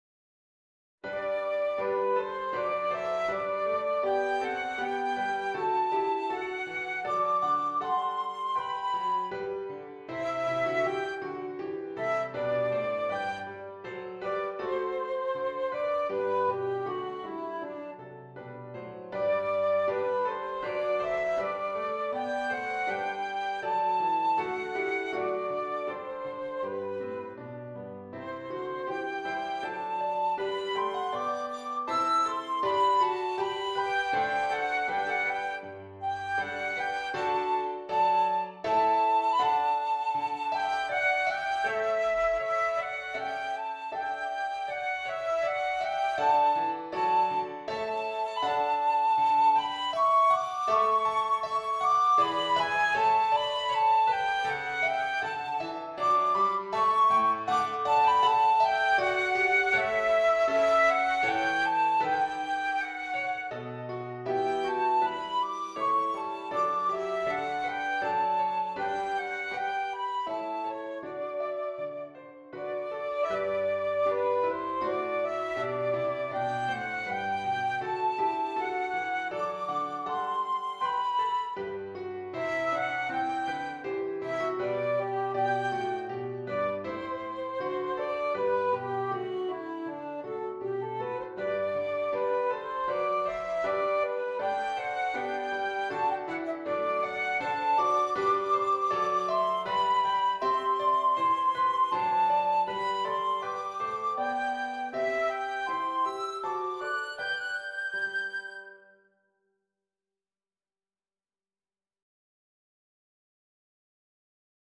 Flute and Keyboard